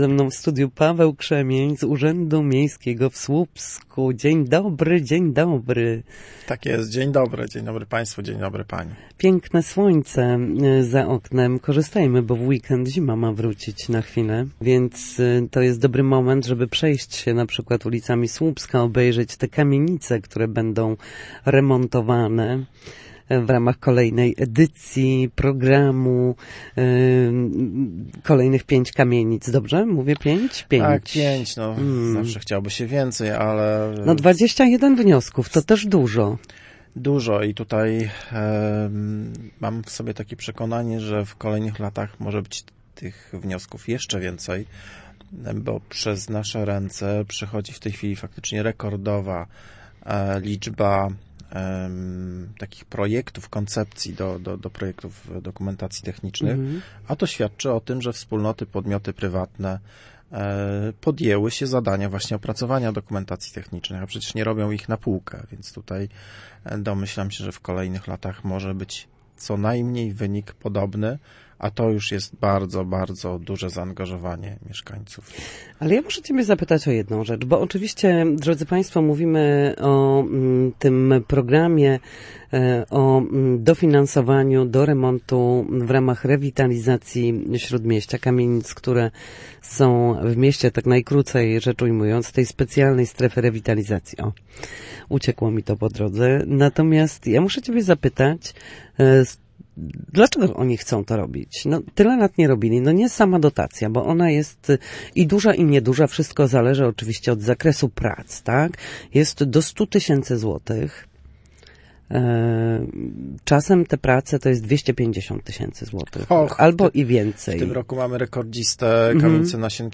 W Słupskim Studiu Radia Gdańsk gościliśmy dziś